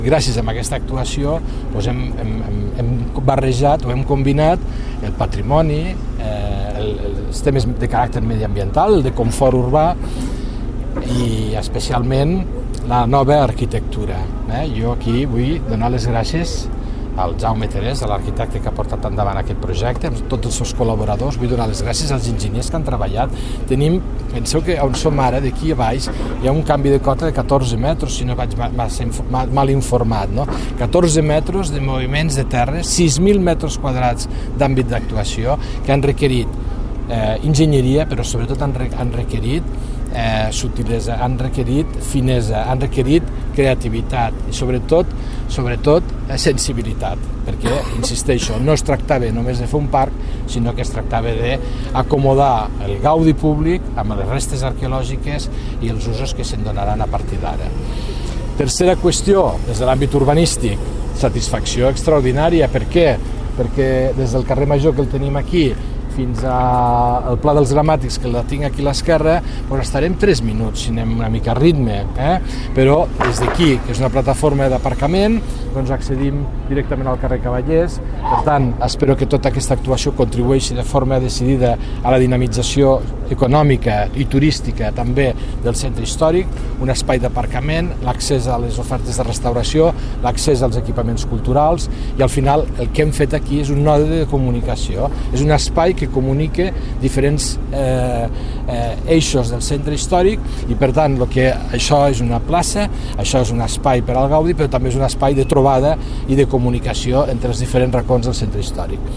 tall-de-veu-de-felix-larrosa-sobre-el-projecte-de-la-cuirassa